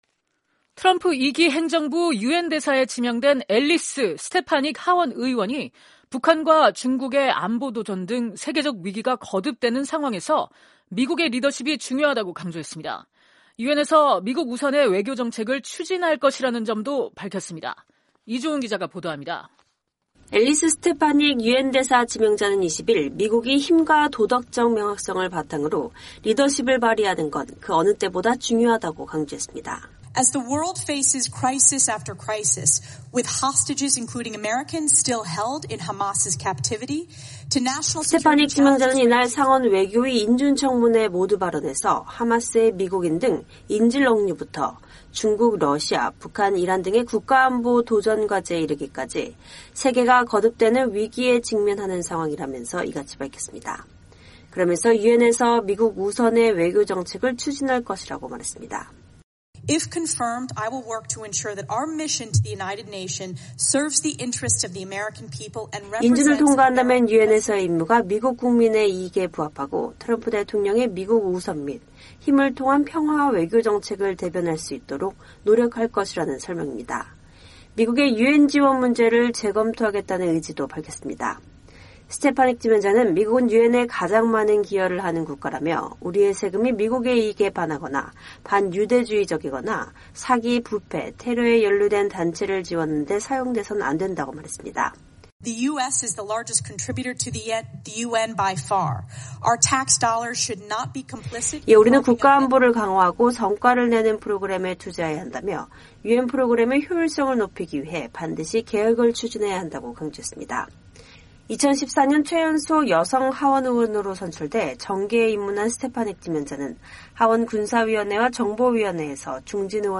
엘리스 스터파닉 유엔대사 지명자가 2025년 1월 21일 상원 외교위 인준 청문회에서 발언하고 있다.